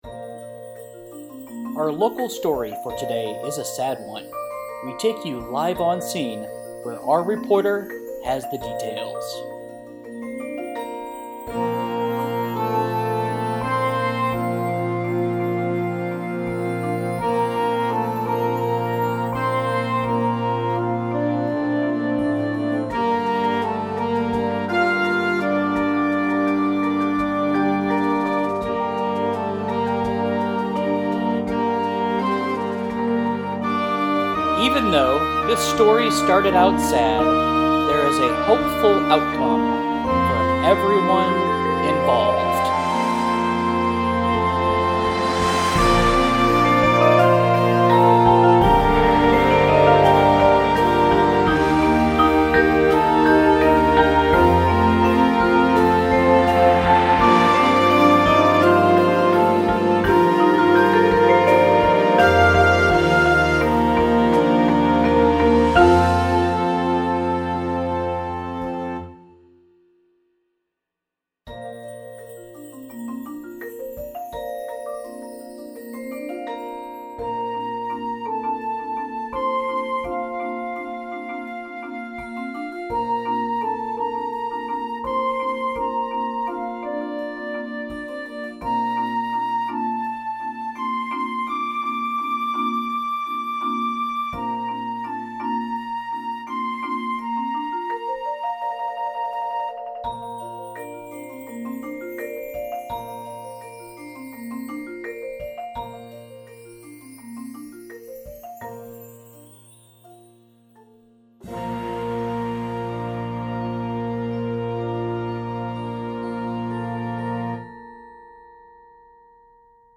• Front Ensemble with Drum Set